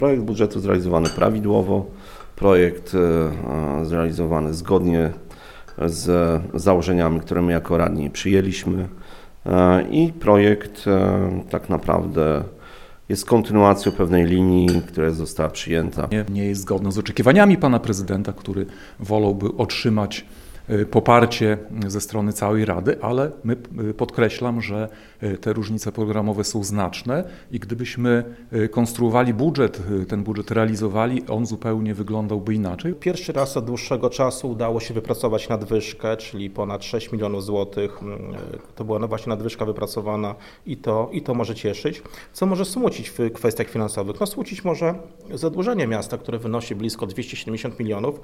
Taka dyskusja zdominowała też Suwalski Magazyn Samorządowy w Radiu 5, gdzie głos zabrali przedstawiciele klubów Grzegorz Gorło z „Łączą nas Suwałki”, Jacek Juszkiewicz z Prawa i Sprawiedliwości oraz Karol Korneluk z Koalicji Obywatelskiej.